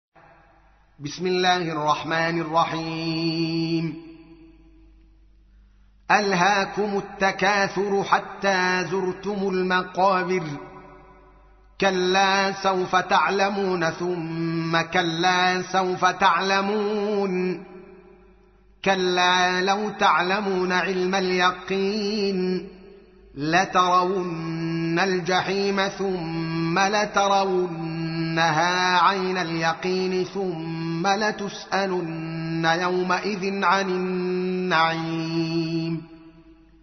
تحميل : 102. سورة التكاثر / القارئ الدوكالي محمد العالم / القرآن الكريم / موقع يا حسين